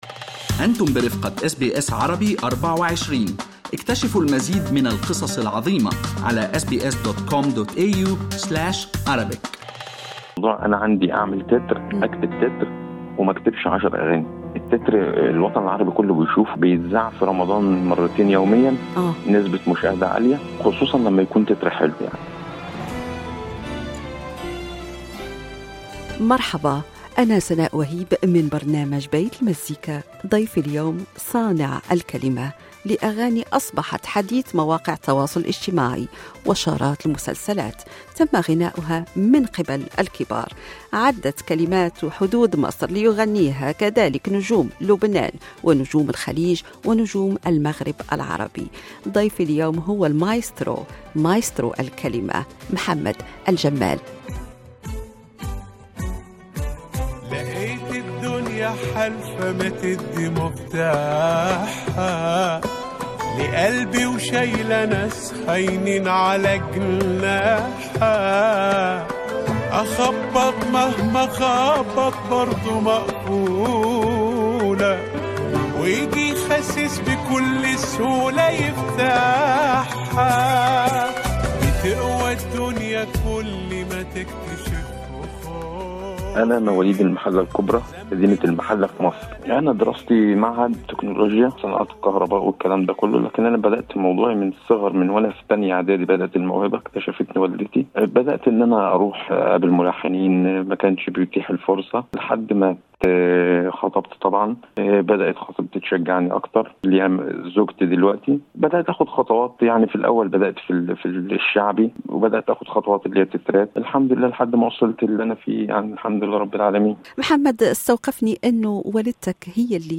وفي لقاء حصري مع برنامج لبيت المزيكا